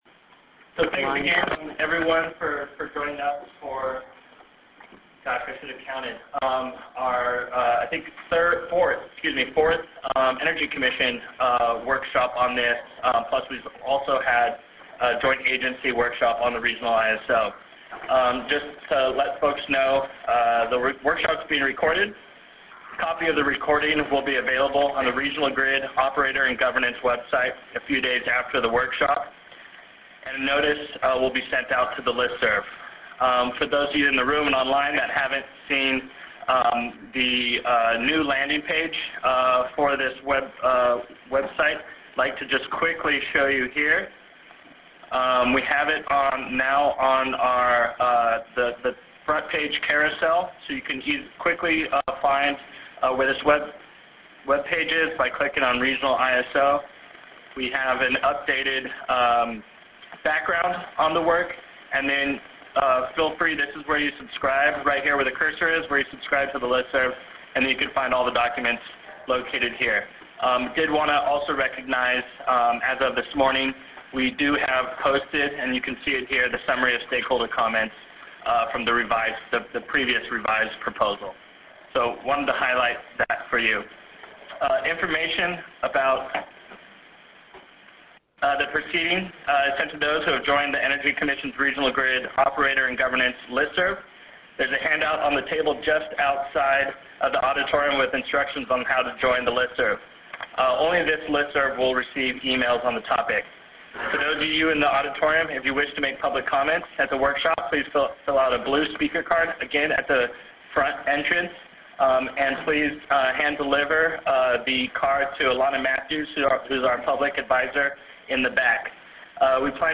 Library | California Energy Commission and California Governor's Office workshop Oct 17, 2016 | California ISO